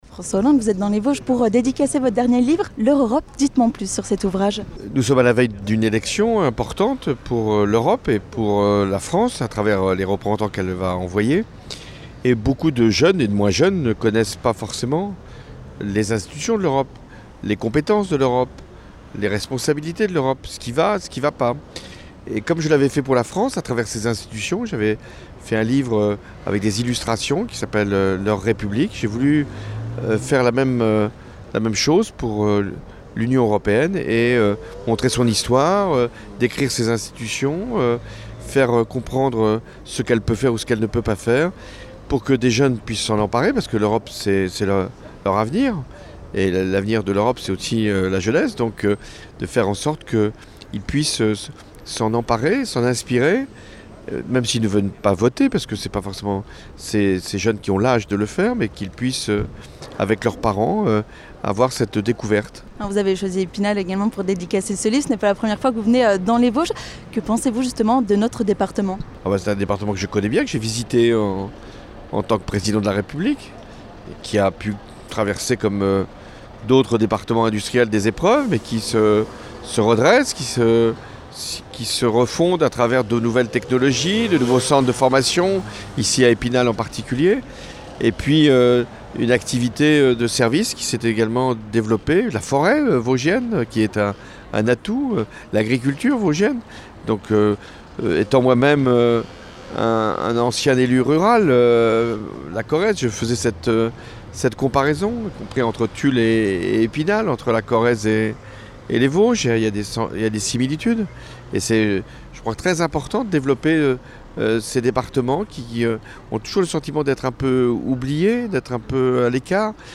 François Hollande était présent à la librairie Quai des Mots pour dédicacer son dernier ouvrage, « Leur Europe ». Habitué des déplacements dans notre département, nous lui avons tendu notre micro afin de lui demander ce qu'il pensait des Vosges.